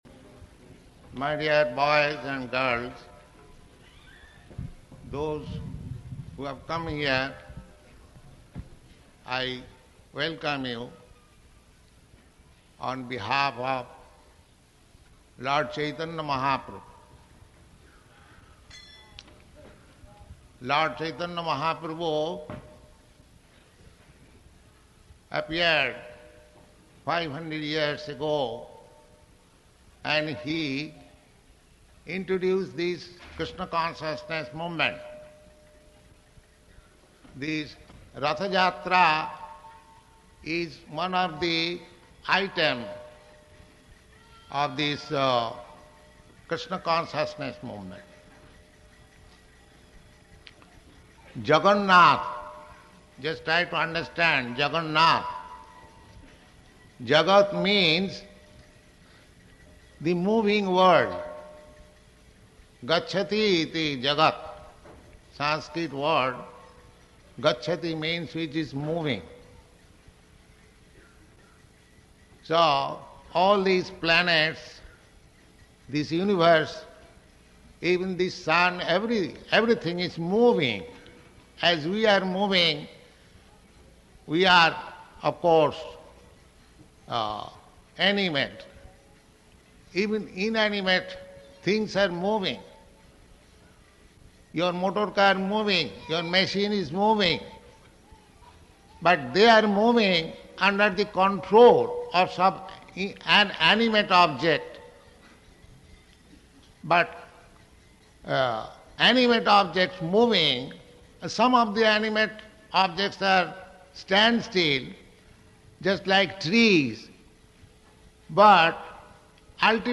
Ratha-yātrā Address
Type: Lectures and Addresses
Location: San Francisco